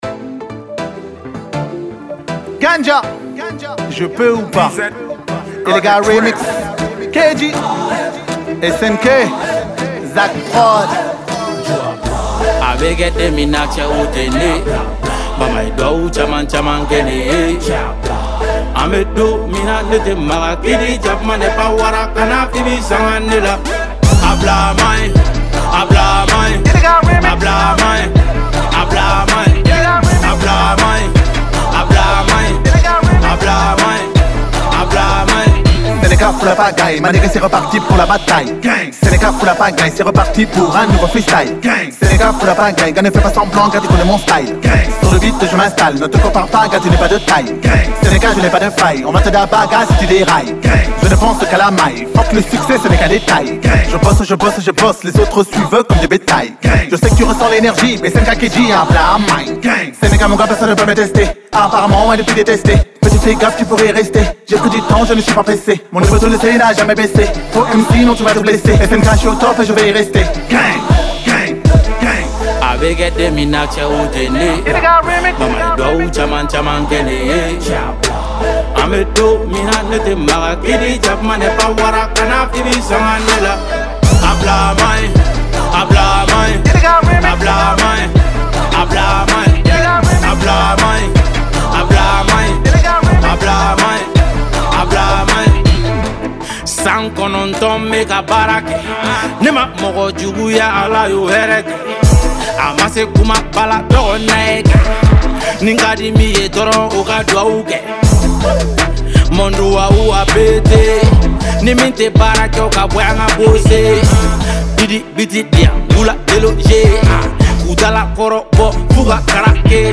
musique Mali rap hip-hop.